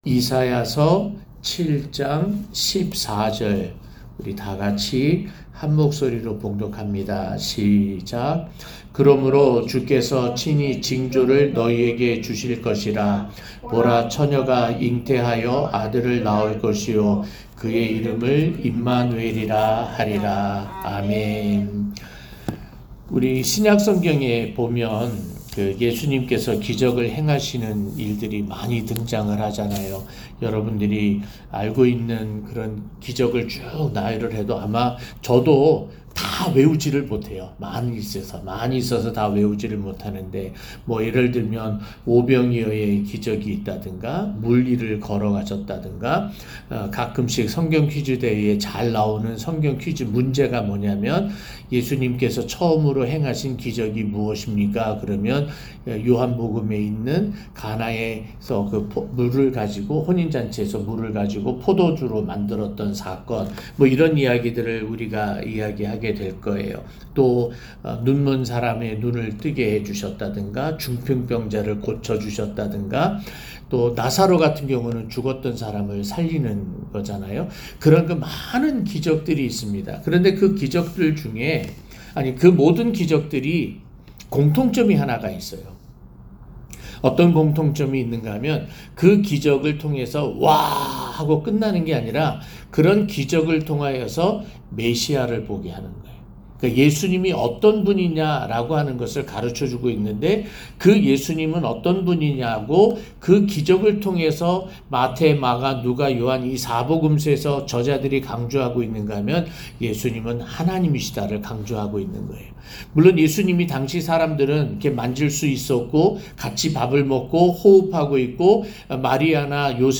동정녀에게서 나신 메시야-성탄새벽설교 (사 7:14)